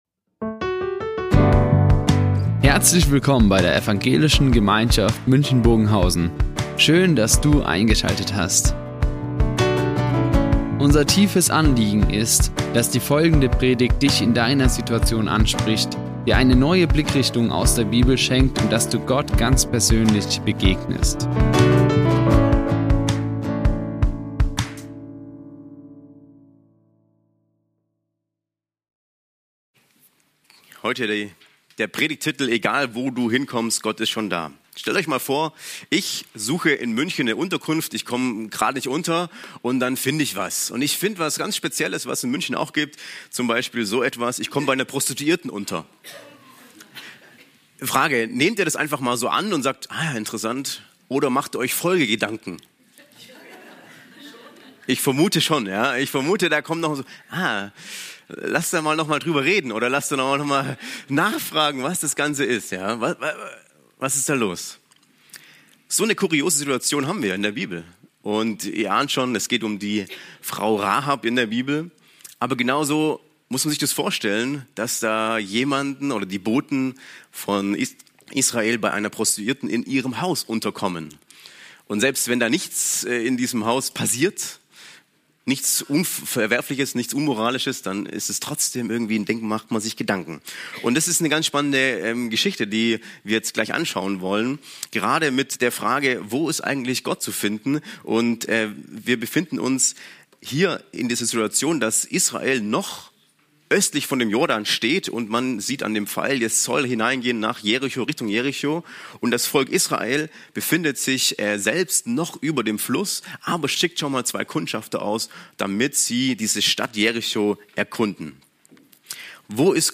Egal wo du hinkommst - Gott ist schon da | Predigt Josua 2 ~ Ev.
Gott ist schon da" Die Aufzeichnung erfolgte im Rahmen eines Livestreams.